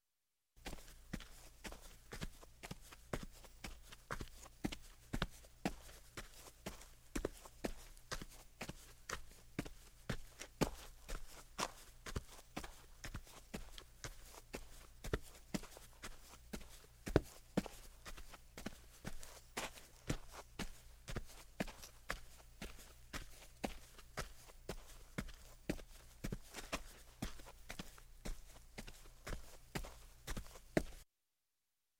Звуки шагов по земле
На этой странице собрана коллекция реалистичных звуков шагов по различным типам земной поверхности.
Шум торопливых шагов